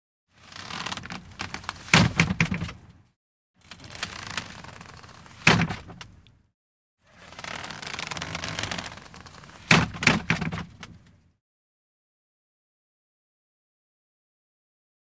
Older Car Seat Adjust Forward And Back With Clunks And Vinyl Seat Creaks.